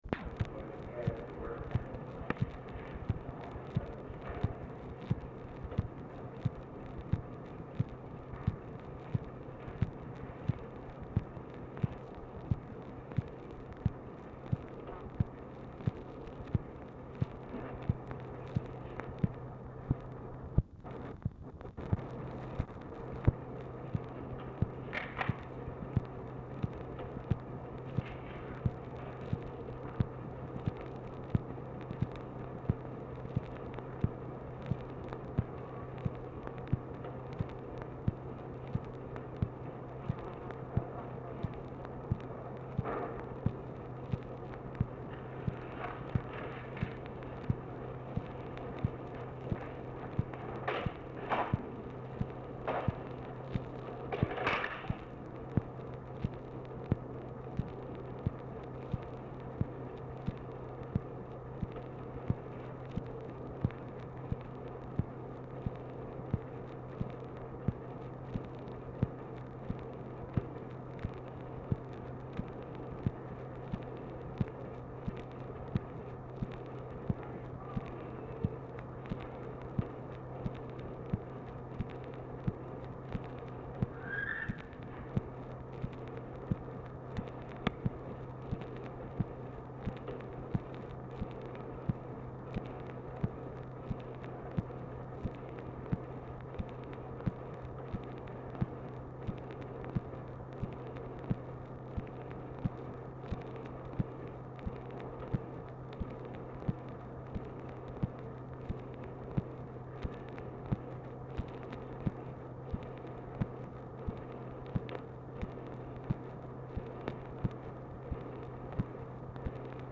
Title Telephone conversation # 3722, sound recording, OFFICE NOISE, 6/12/1964, time unknown Archivist General Note CONTINUES FROM PREVIOUS RECORDING?
Dictation belt